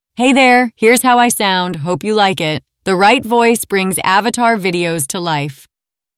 Vivacious Veronica - Excited 🤩
🌍 Multilingual👩 Женский
Пол: female
Этот скрипт отображает тайские голоса и голоса с поддержкой множественных языков из HeyGen API с возможностью фильтрации.